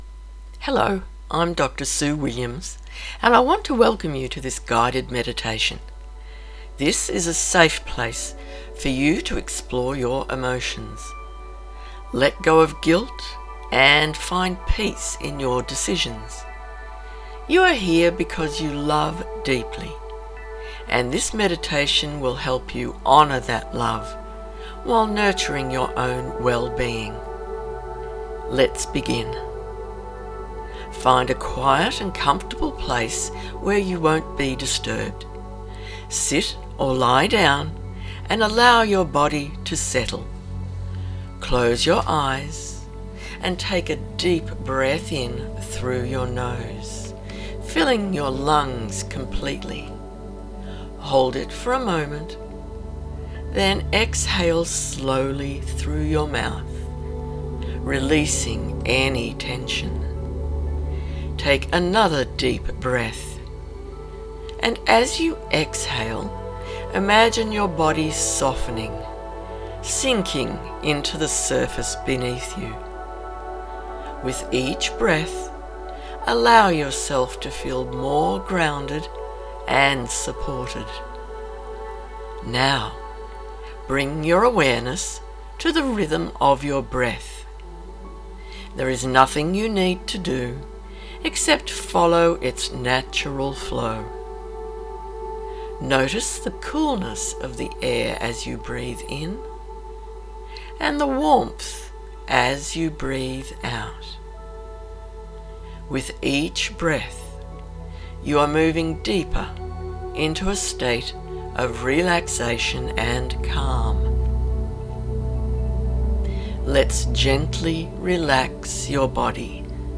GUIDED MEDITATION: